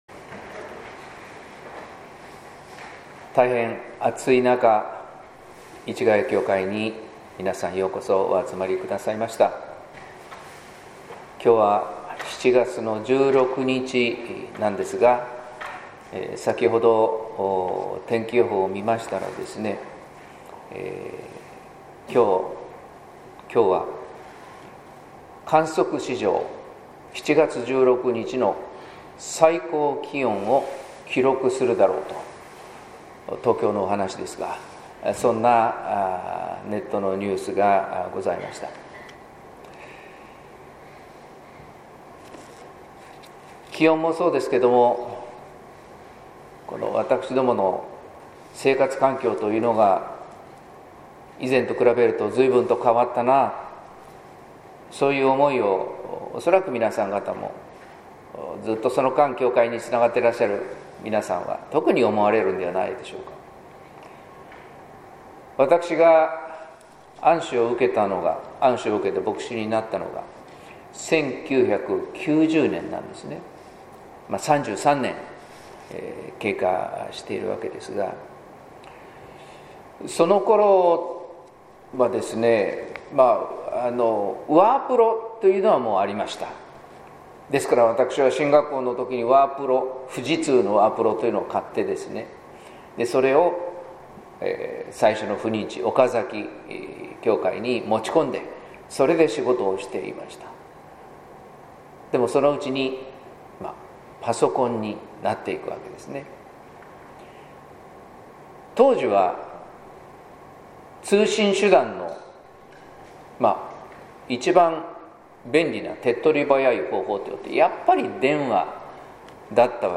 説教「確かな証拠」（音声版）